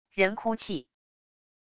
人哭泣wav音频